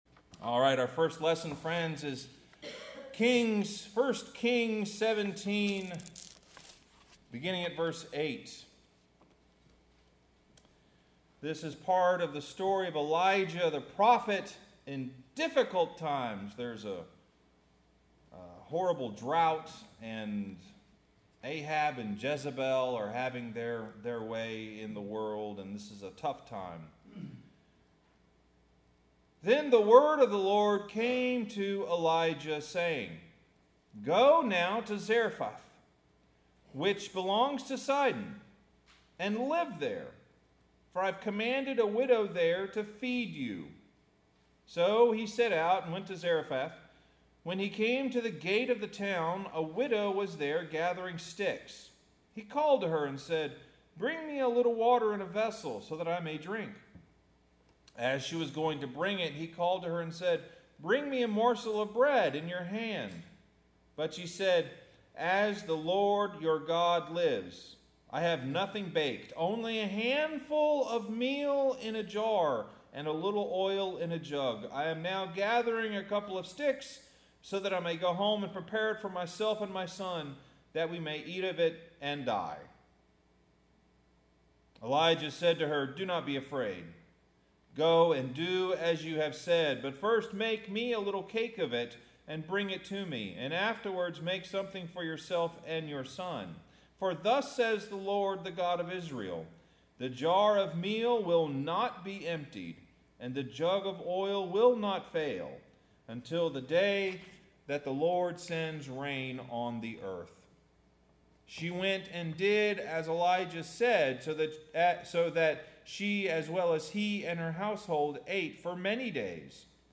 Sermon – If I Had a Million Dollars